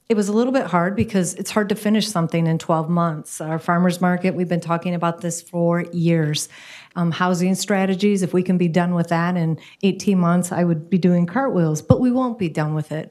Mayor Patricia Randall says they had to whittle down the list to items that were ongoing priorities.